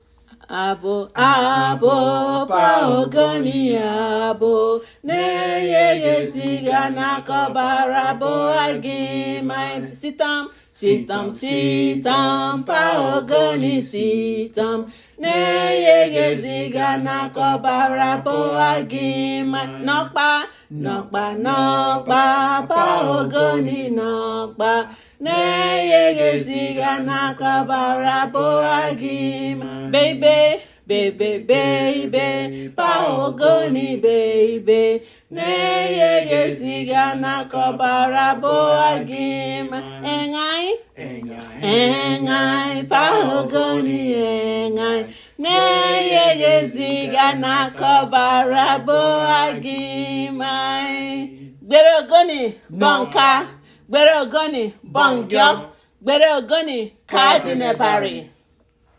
Ogoni-anthem.mp3